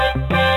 video_start.wav